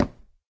wood4.ogg